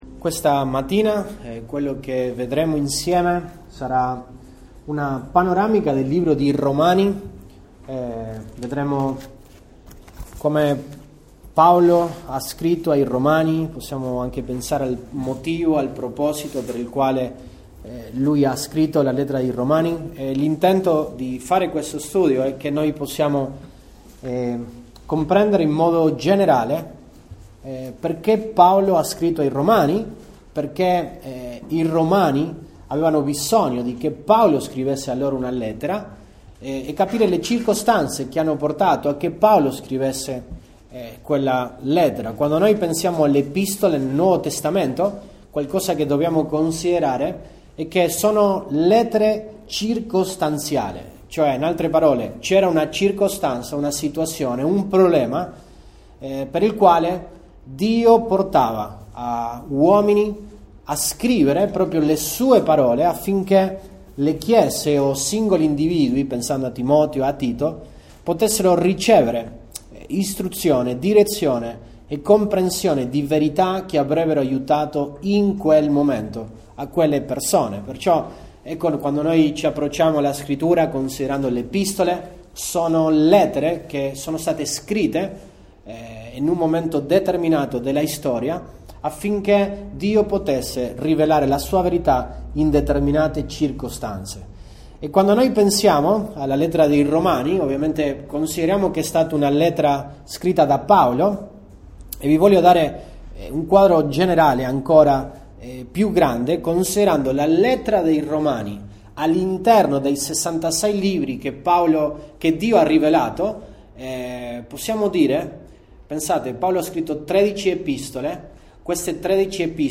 Mag 27, 2024 Panoramica della lettera di Paolo ai Romani MP3 Note Sermoni in questa serie Panoramica della lettera di Paolo ai Romani.